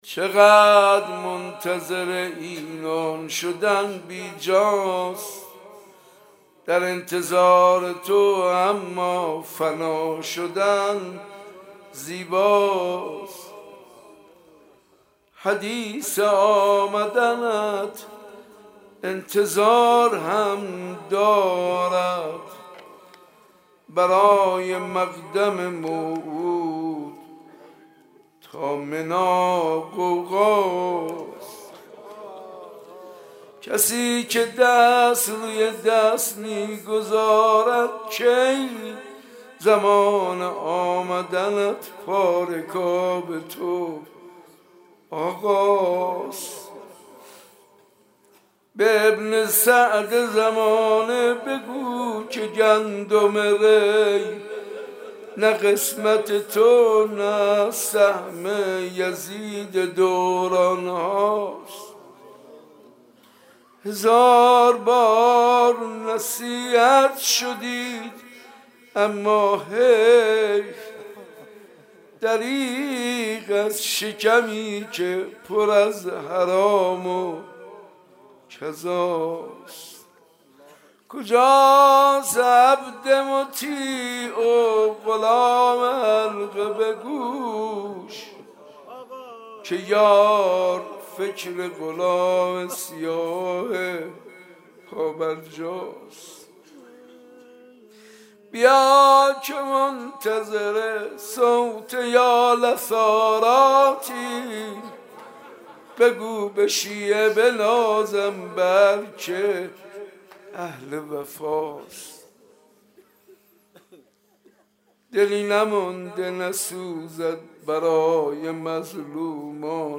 مناجات با امام زمان عجل الله تعالی فرجه الشریف